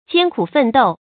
艰苦奋斗 jiān kǔ fèn dòu
艰苦奋斗发音
成语正音斗，不能读作“dǒu”。